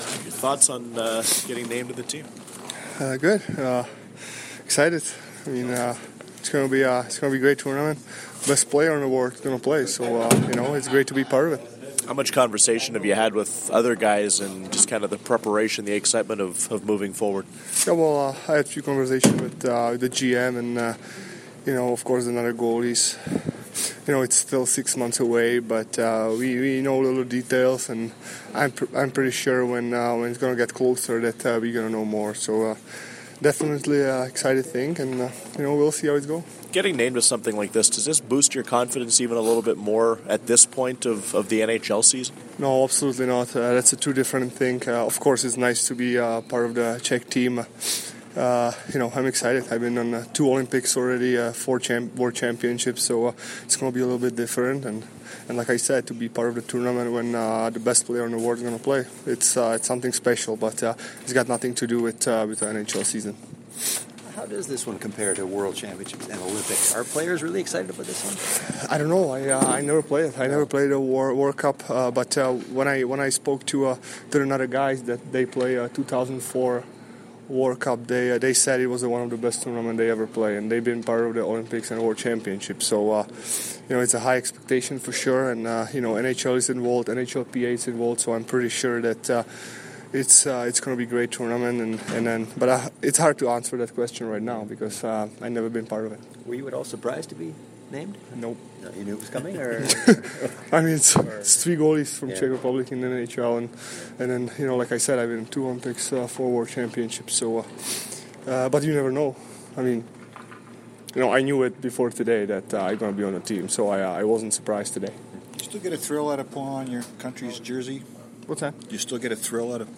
Ondrej Pavelec spoke today following skate about what it means to be selected and put on the Czech jersey: